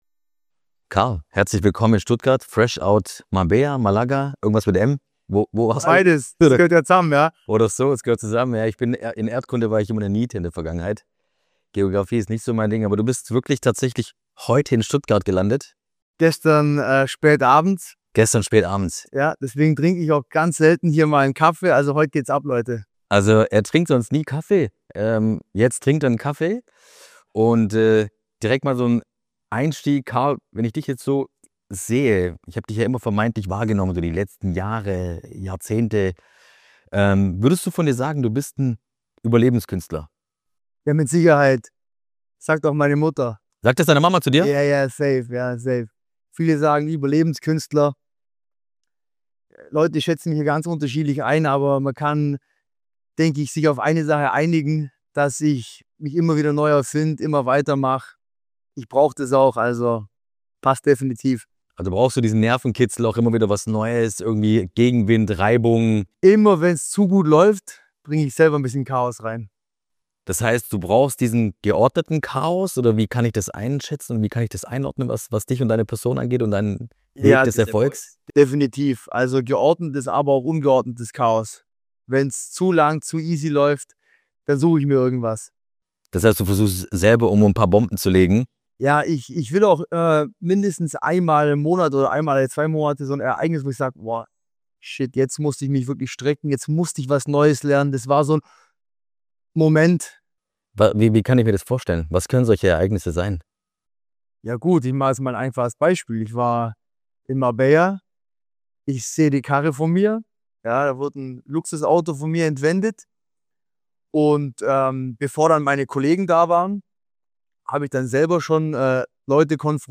Ein Gespräch über Macht, Motivation und einen Mann, der nicht mehr nur zuschauen will.